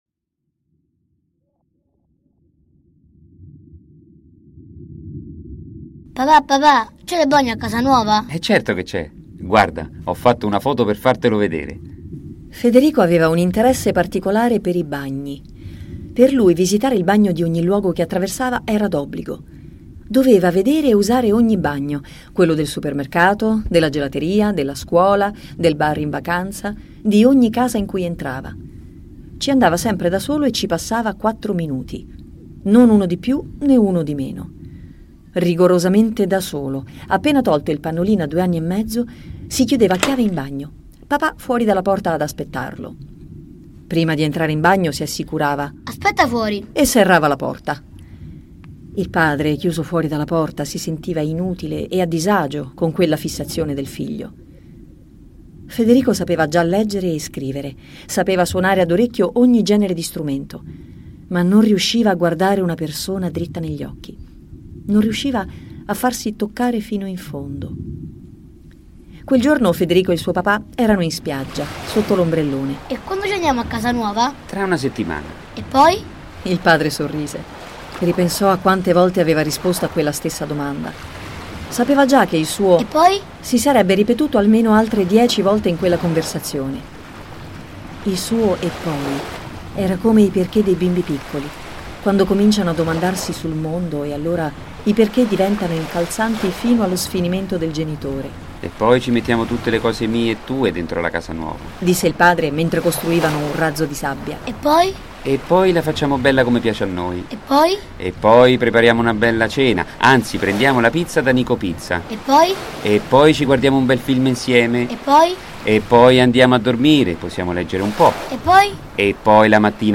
racconto letto da